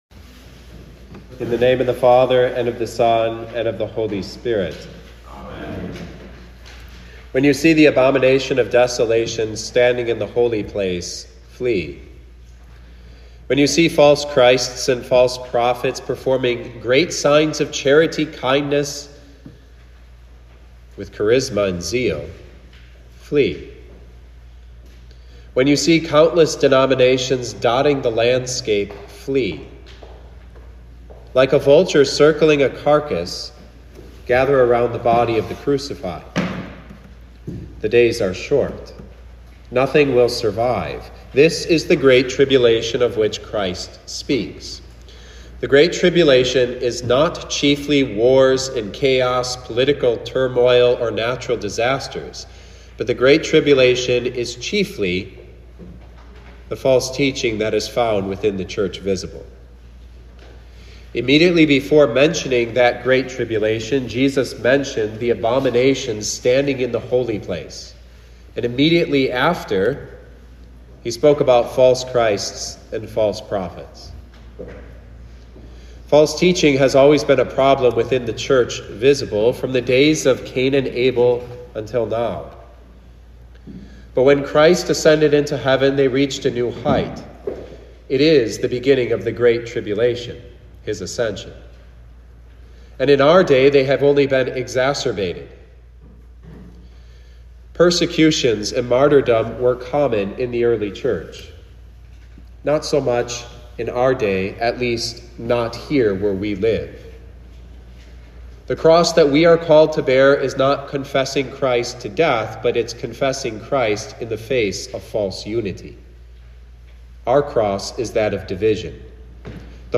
2024 Third Last Sunday of the Church Year Preacher